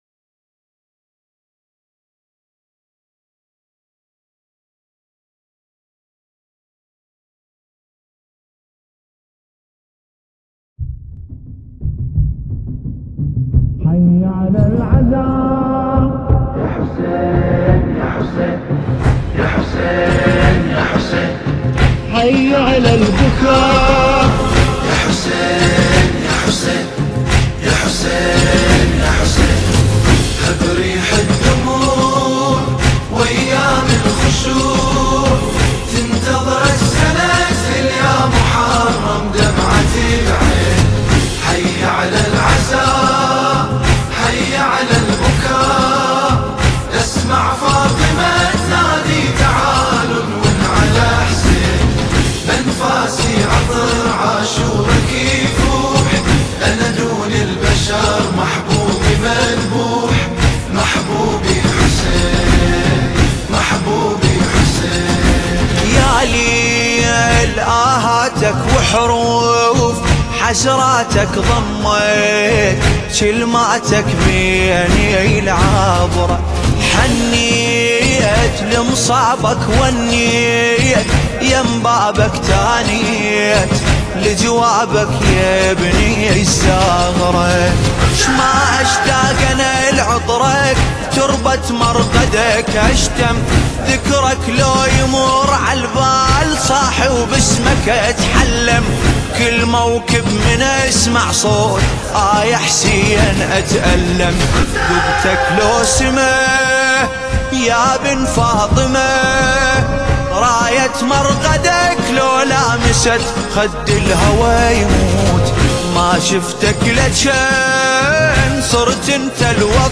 مداحی عربی فارسی